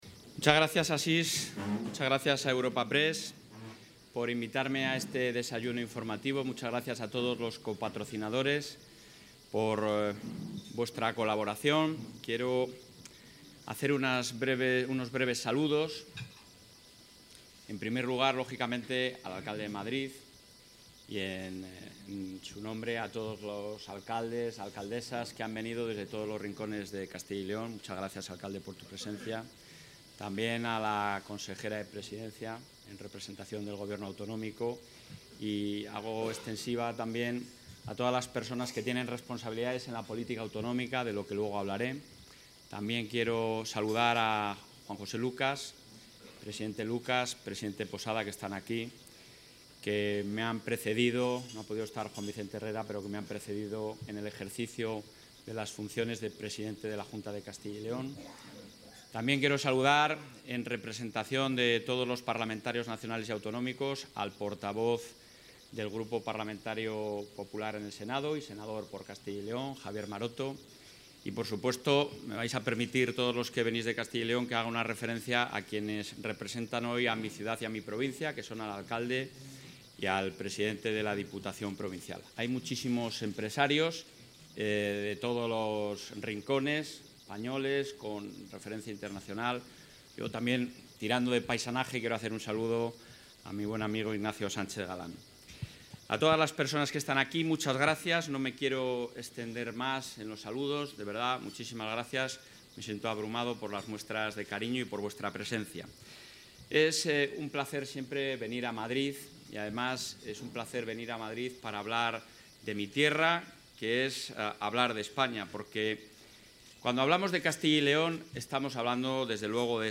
Intervención del presidente.
El presidente de la Junta de Castilla y León, Alfonso Fernández Mañueco, ha participado esta mañana en un desayuno informativo organizado por Europa Press, donde ha puesto de manifiesto el éxito, la eficacia y los logros de un modelo autonómico que está funcionando pero que en la actualidad está viéndose asfixiado por un Gobierno que no está devolviendo el dinero que debe y está paralizando la presentación de unos presupuestos, lo que a su vez ralentiza la acción de las comunidades.